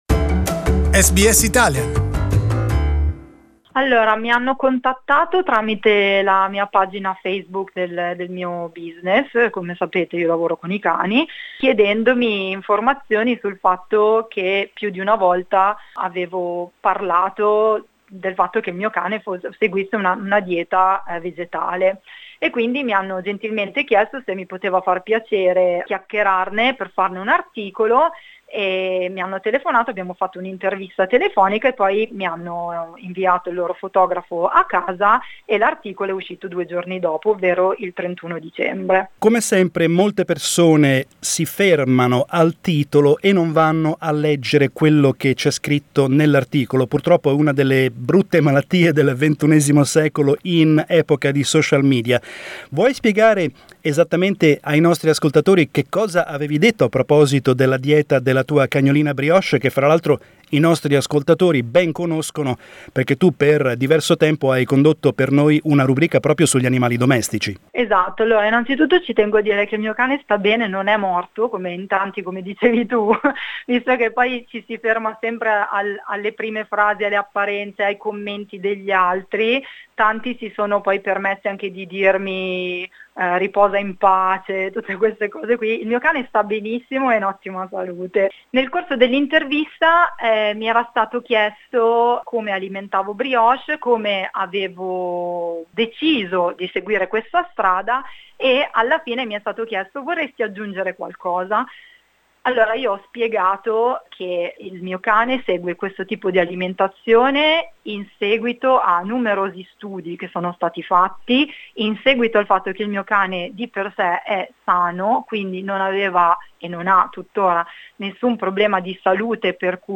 We spoke to her and asked her about her choice to feed Brioche with a plant-based diet and to find out how her interview went viral online.